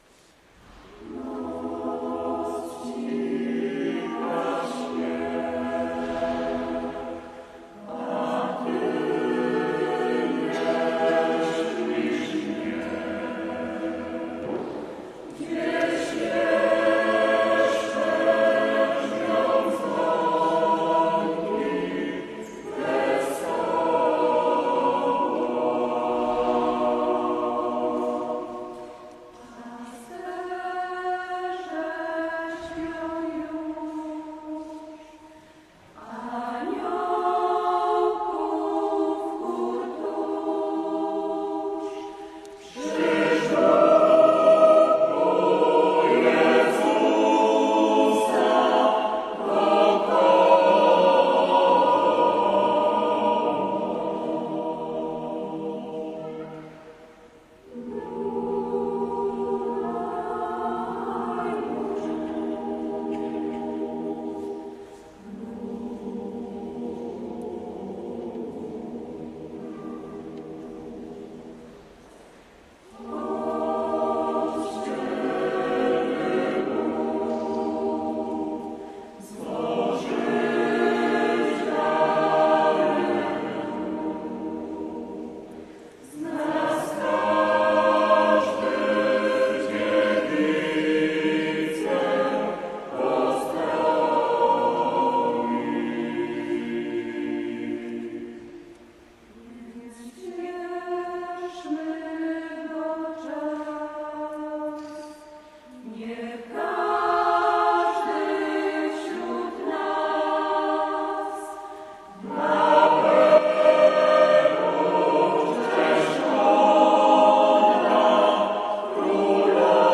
• Godz. 19.00 – Koncert organowo-chóralny